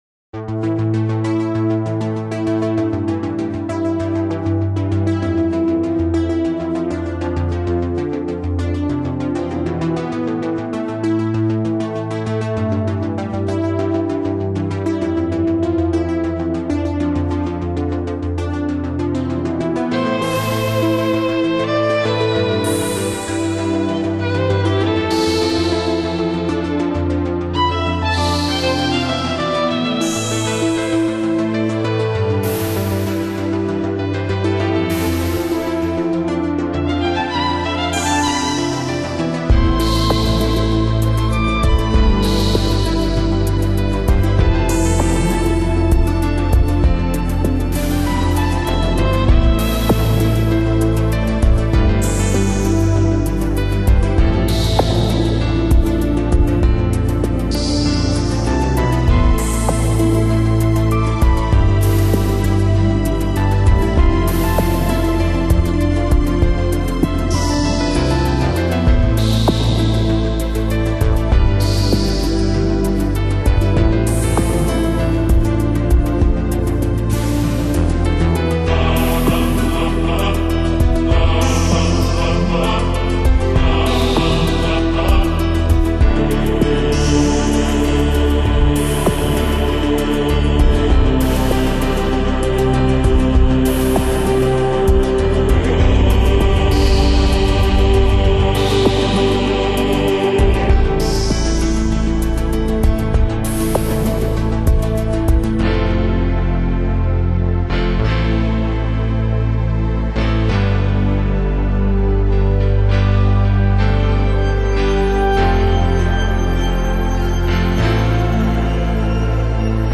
音乐类型: Newage
丛林的深处，雾霭四起。女人轻柔的和声，从林间响起，柔美的电子音乐雕刻着森林的神秘，
俨然是森林的主宰。钢琴悠悠响起，男人们的身影消失在雾霭中。